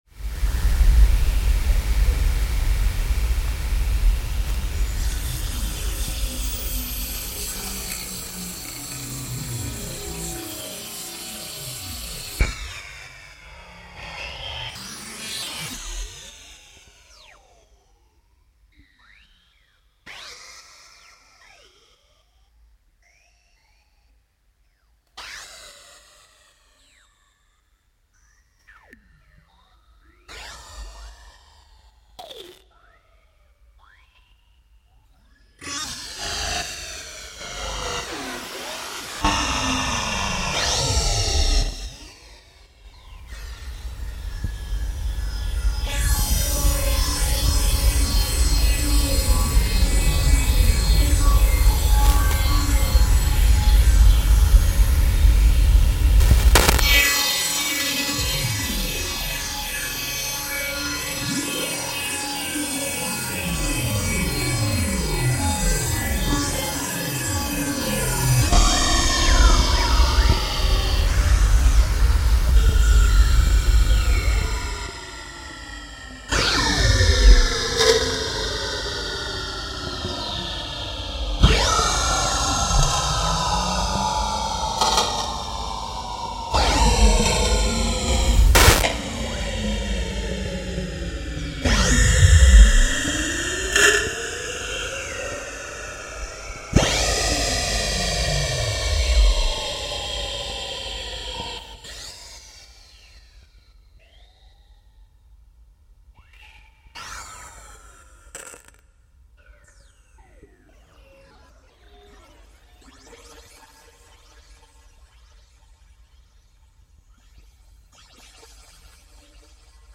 Electrical storm in Perth reimagined